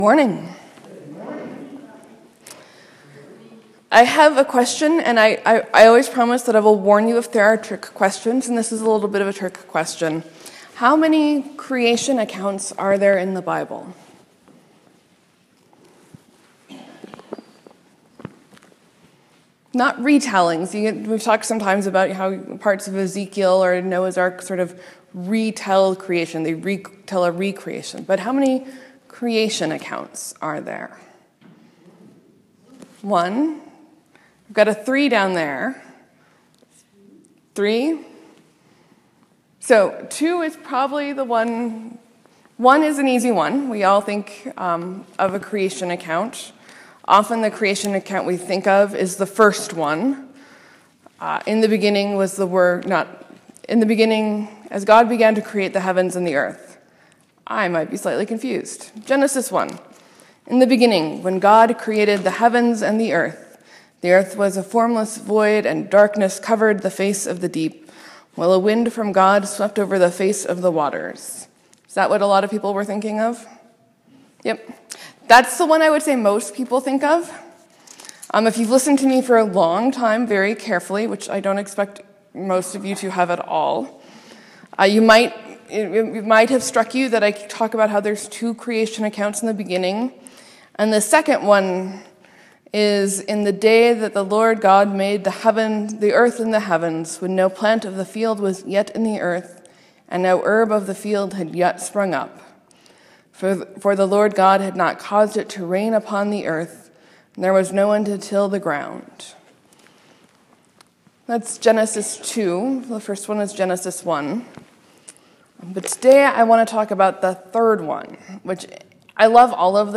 Sermon: Sometimes the Holy Spirit is fire, or wind, or a breath.